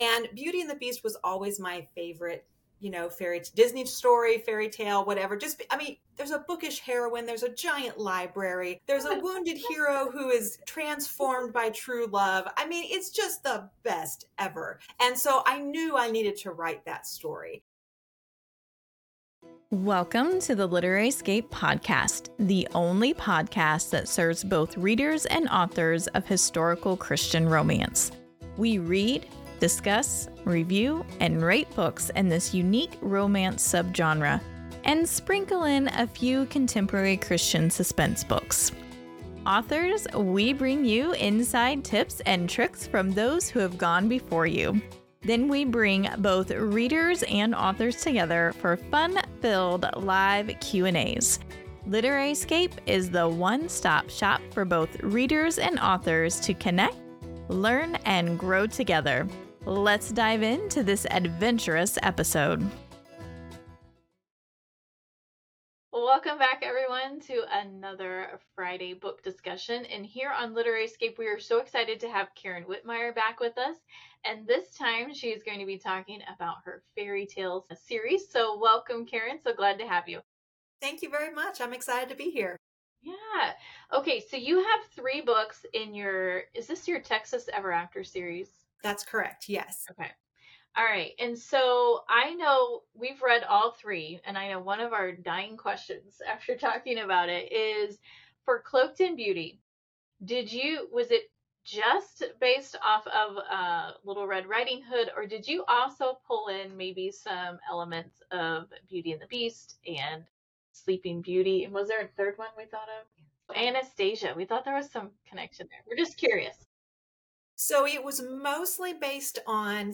If you missed our live discussion, here's everything you need to know about these delightful Western retellings.